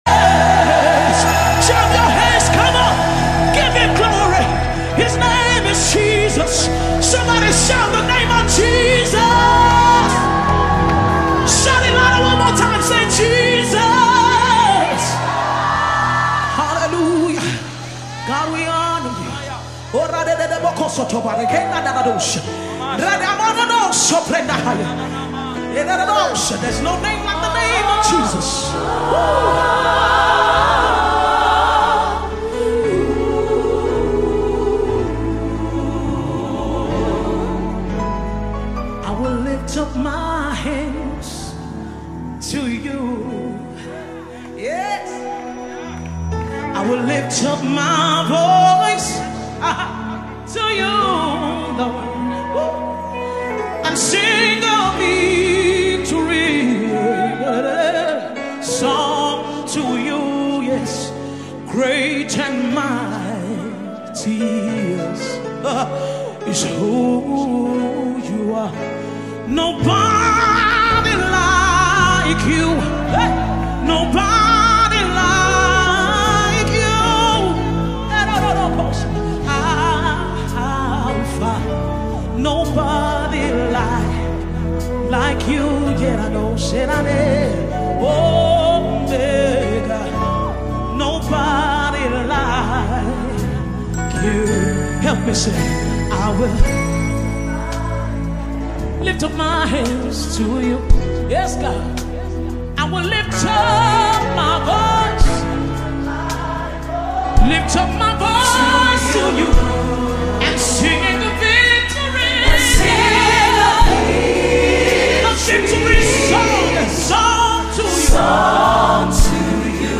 Energetic gospel music minister
Live Audio and Video recording
The song was recorded live